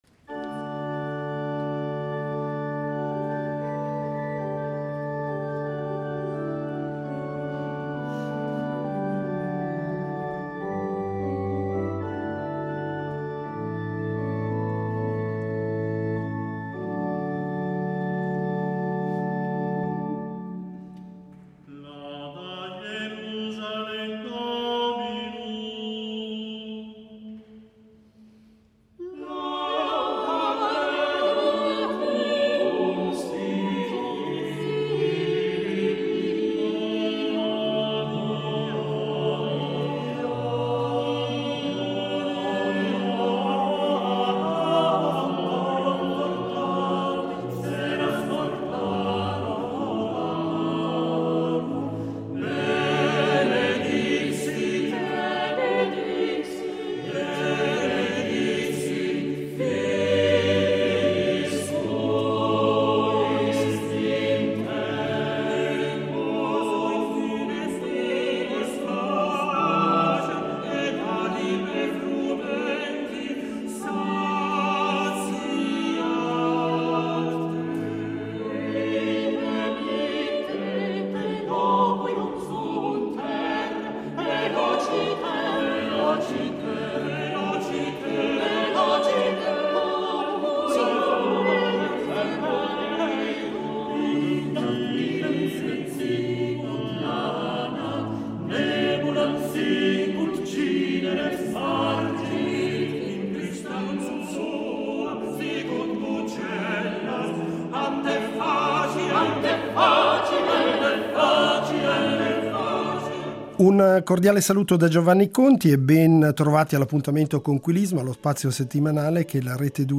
Il concerto propone musiche polifoniche e monodiche di differente provenienza e di vari autori sia nello stile di ampia polifonia, eseguita in San Marco, sia di cromatici melismi bizantini che da secoli hanno fatto eco sotto le cupole di Aghia Sophia.